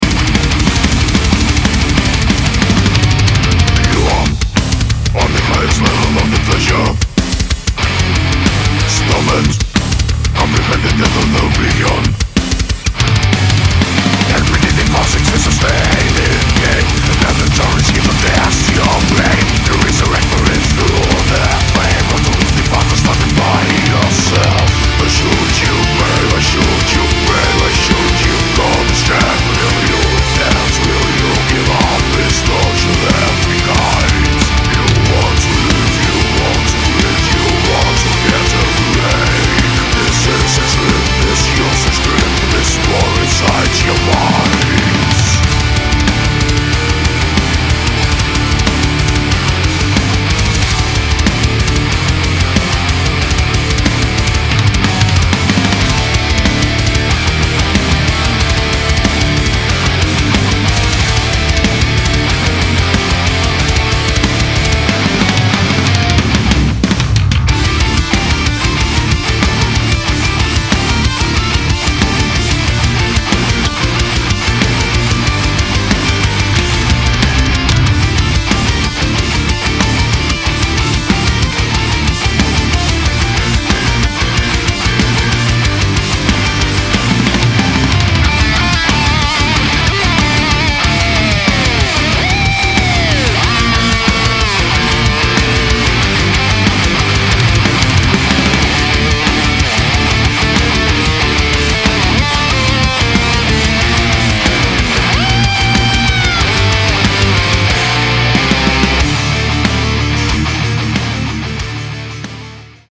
����������� Melodic Death/Folk ��������...